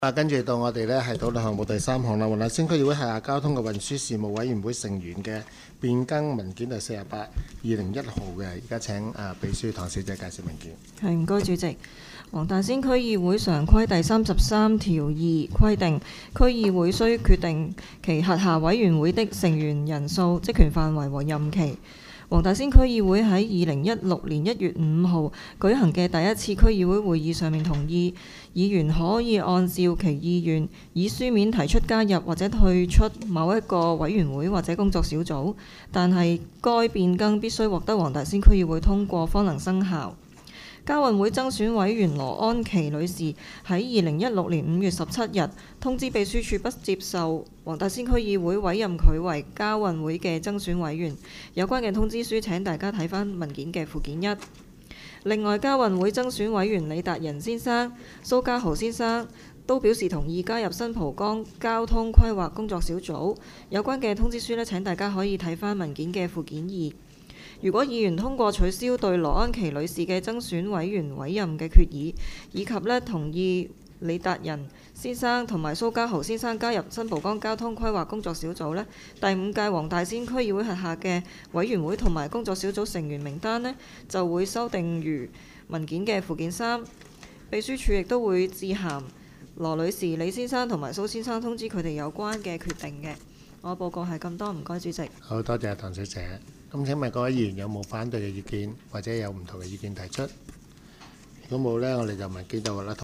区议会大会的录音记录
黄大仙区议会第五次会议